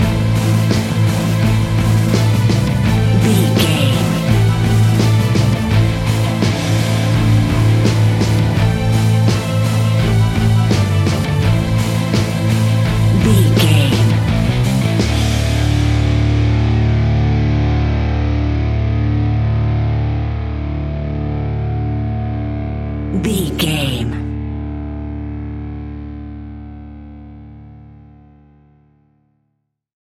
royalty free music
Epic / Action
Aeolian/Minor
Slow
metal
hard rock
heavy metal
horror rock
instrumentals
Heavy Metal Guitars
Metal Drums
Heavy Bass Guitars